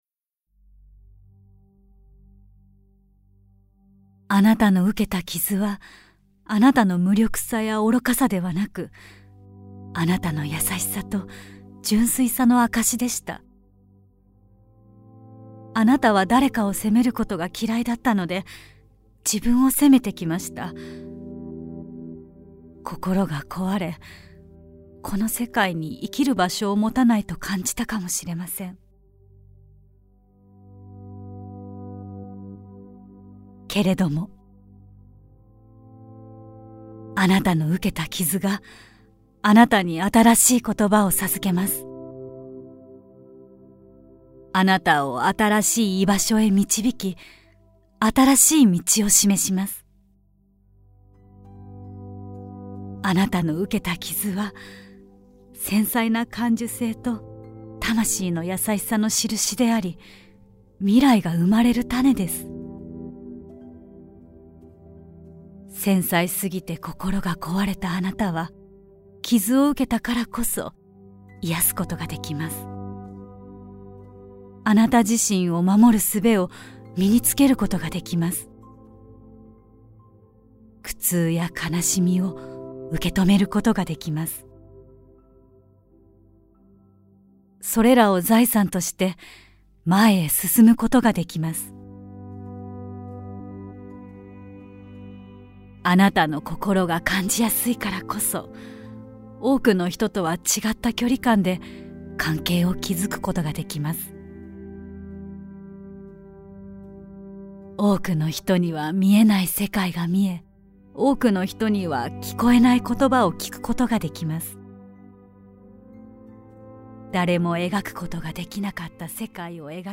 [オーディオブック] 心がこわれた繊細なあなたへ